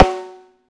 high rim ff.wav